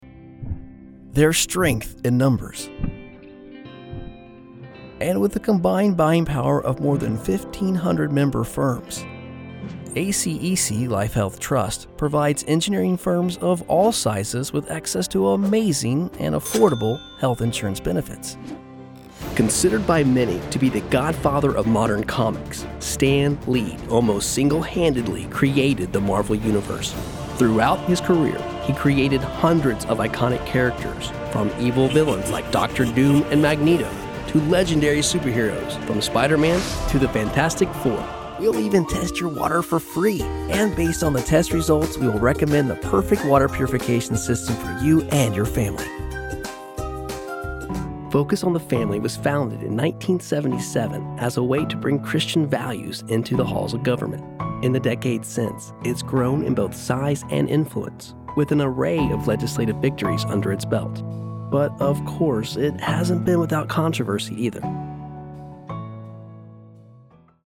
Male
American English (Native)
Confident, Cool, Engaging, Warm, Witty, Versatile
General American-Mid West (Native), American Southern
Microphone: Sennheiser MKH416 & Neumann TLM 102
Audio equipment: Apollo Twin X interface, Broadcast Quality Home Studio